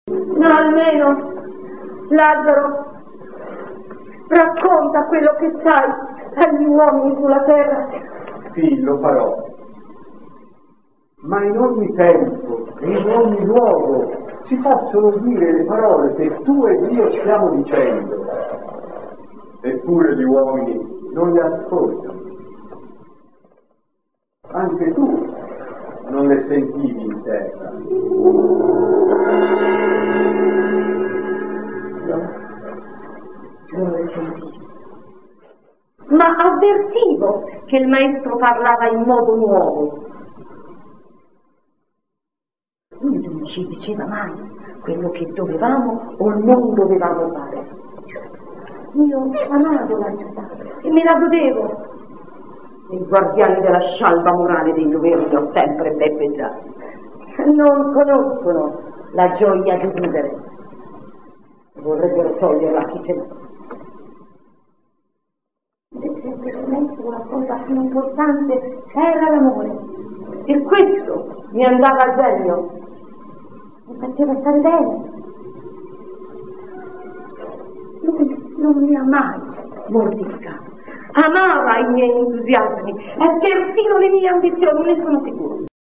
Teatro Giannina Noseda - Scuola Rudolf Steiner - Milano